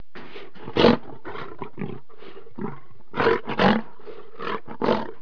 جلوه های صوتی
دانلود صدای حیوانات جنگلی 98 از ساعد نیوز با لینک مستقیم و کیفیت بالا